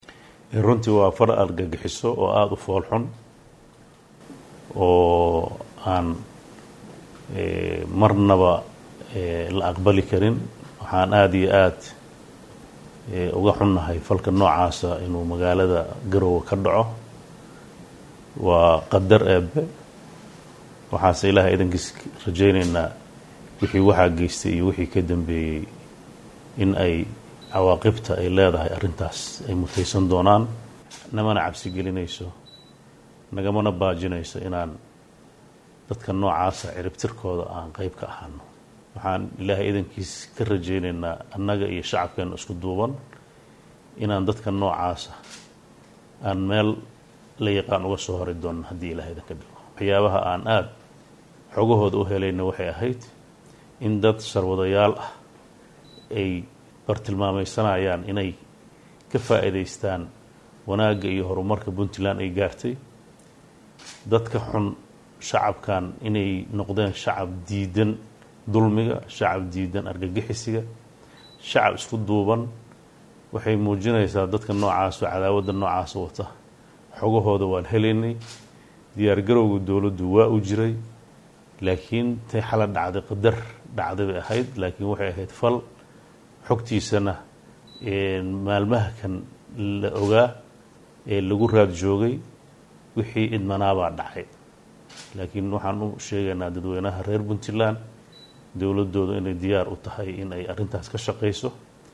Codka-Madaxweynaha-Puntland.mp3